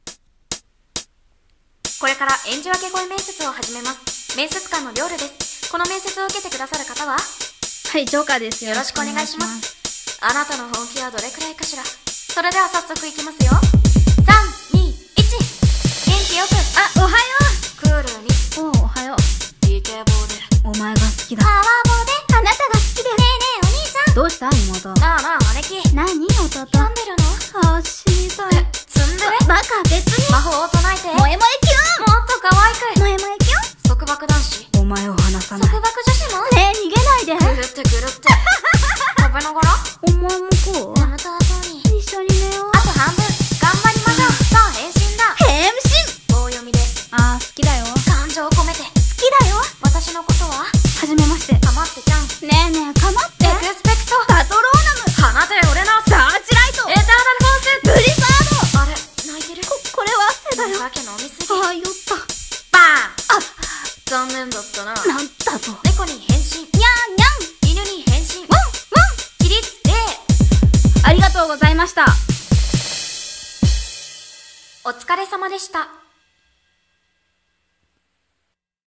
【声面接】演じ分け声面接